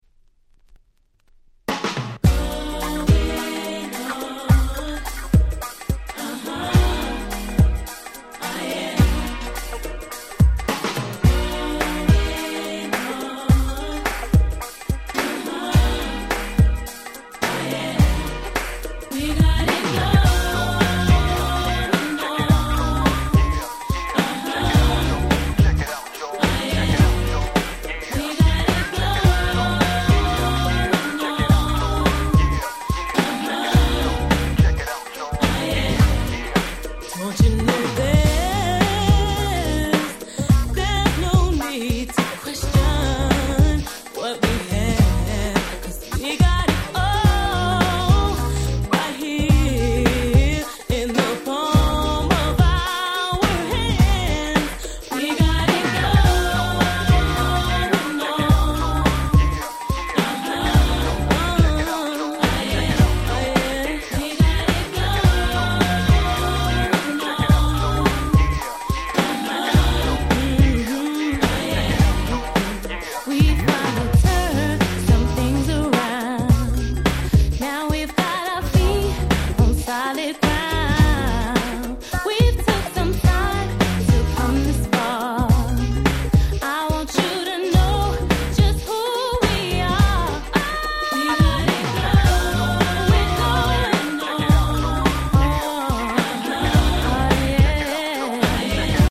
95' Very Nice R&B !!
フロア映えしそうなナイスなBeat感の凄く良いR&Bなのです！
ピーヒャラ系シンセもバッチリ！！